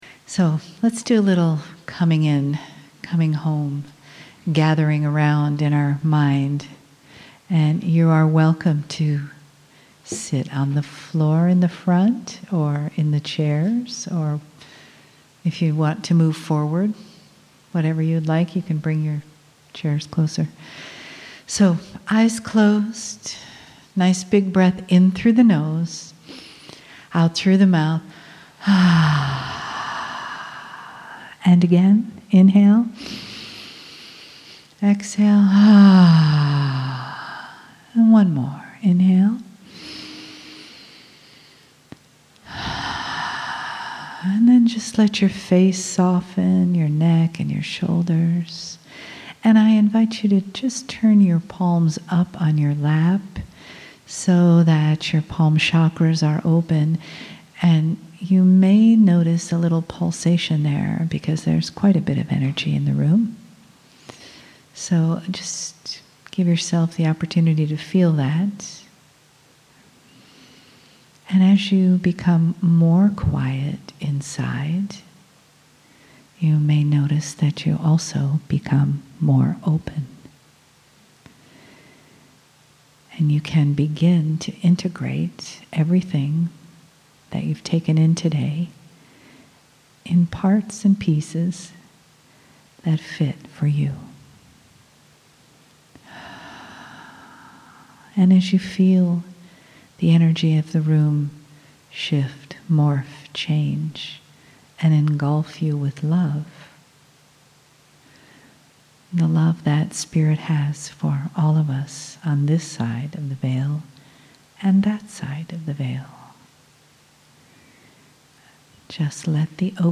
Back ATLANTA, GEORGIA Saturday